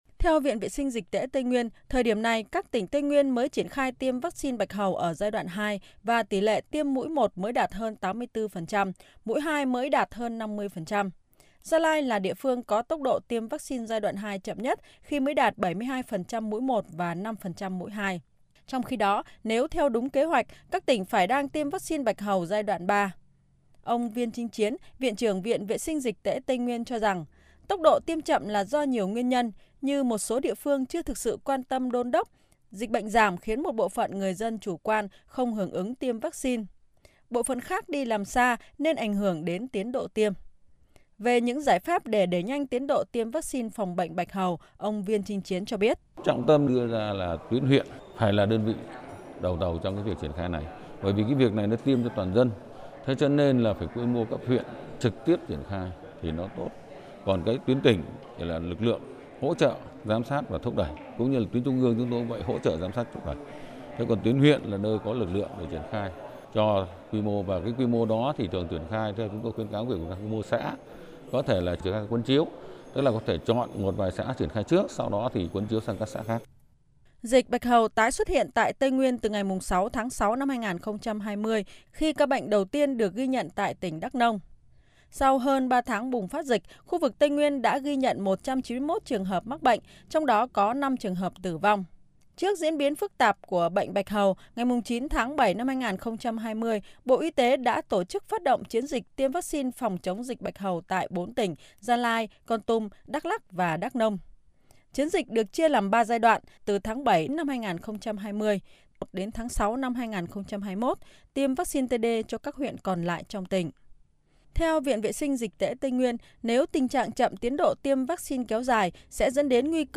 THỜI SỰ Tin thời sự